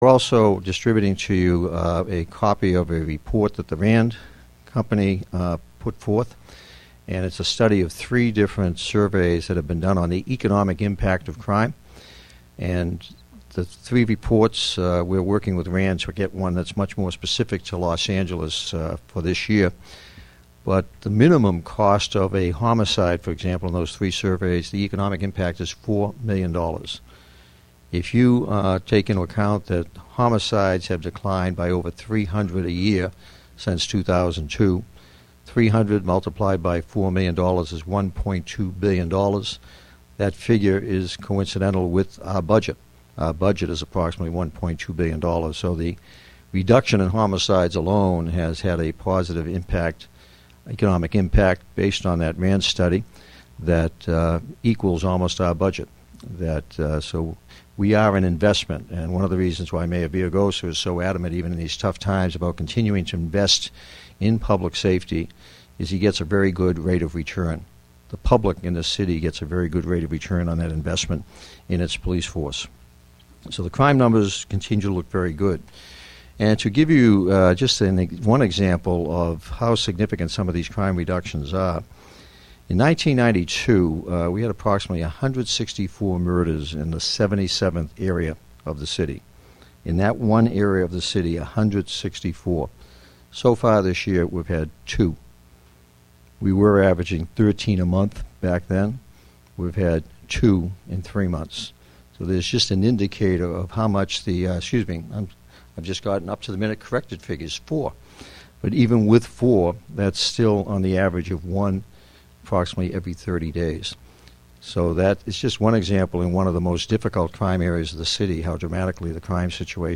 Chief Bratton Hosts Media Availability News Conference for March Topics Include Honors for Heroism and Chief’s Request for Federal Law Enforcement Funds